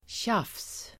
Uttal: [tjaf:s]